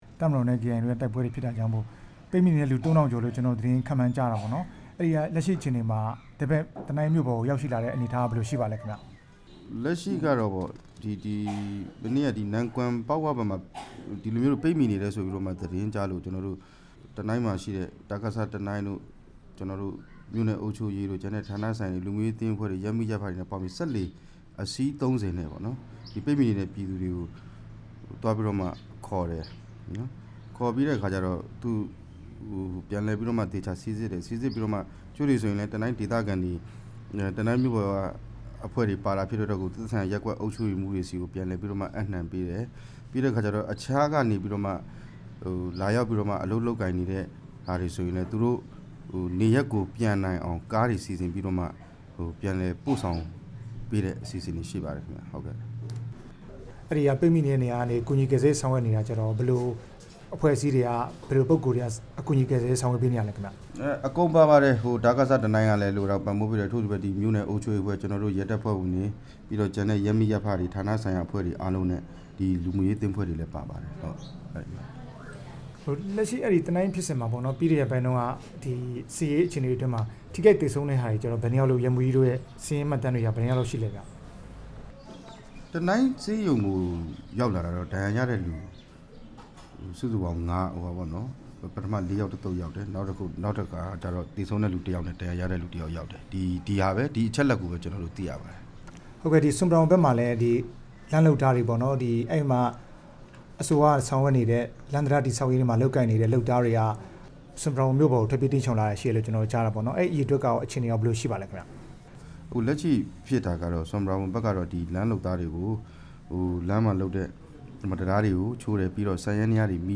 တနိုင်းနဲ့ ဆွမ်ပရာဘွမ်ဘက်က အခြေအနေ ဆက်သွယ်မေးမြန်းချက်